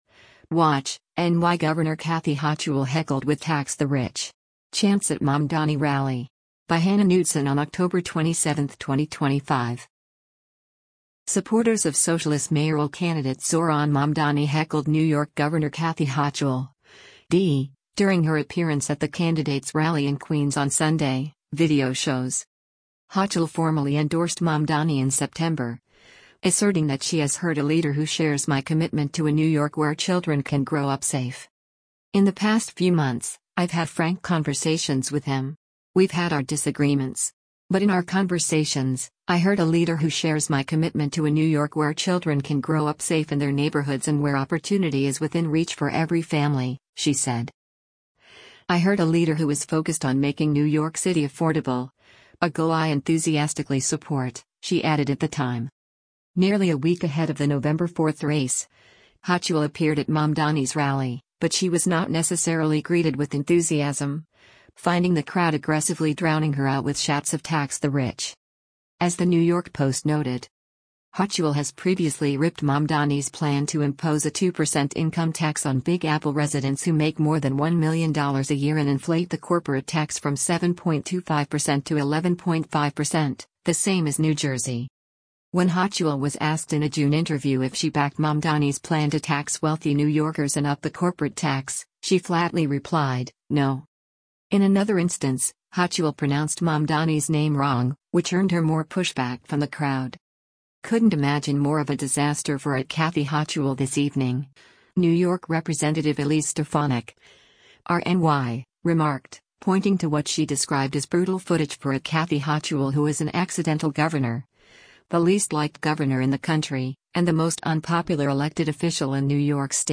Supporters of socialist mayoral candidate Zohran Mamdani heckled New York Gov. Kathy Hochul (D) during her appearance at the candidate’s rally in Queens on Sunday, video shows.
Nearly a week ahead of the November 4 race, Hochul appeared at Mamdani’s rally, but she was not necessarily greeted with enthusiasm, finding the crowd aggressively drowning her out with shouts of “Tax the rich!”
In another instance, Hochul pronounced Mamdani’s name wrong, which earned her more pushback from the crowd.